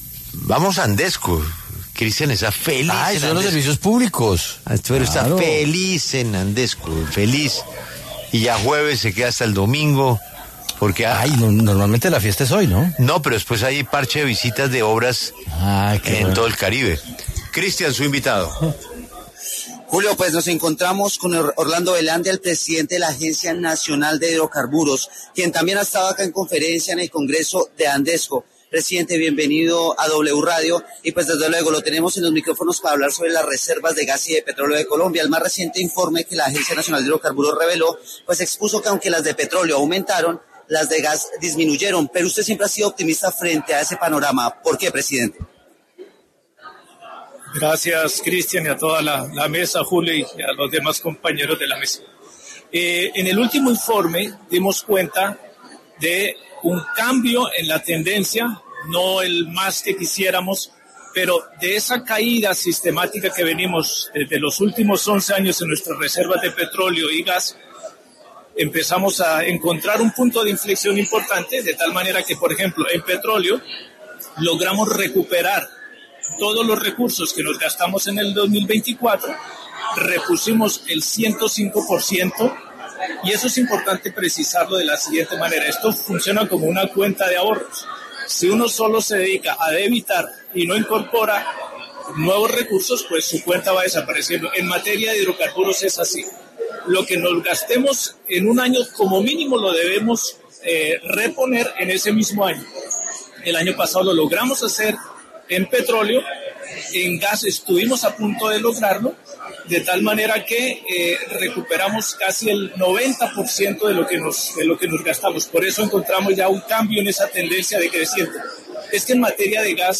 Orlando Velandia, presidente de la Agencia Nacional de Hidrocarburos, pasó por los micrófonos de La W para hablar sobre las reservas de gas y de petróleo en Colombia.